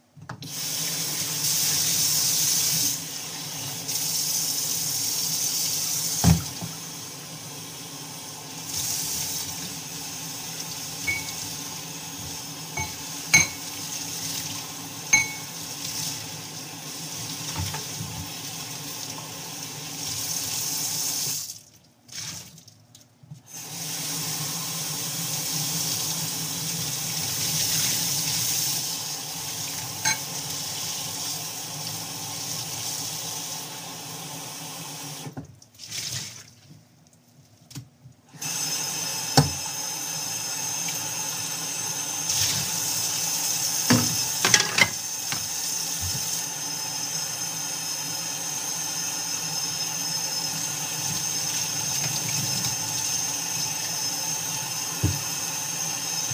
Location: Republic Hall Kitchen
Sounds: Running water, dishes clanking, water turning on and off, dishes being placed in drying rack
Field-Recording-33.mp3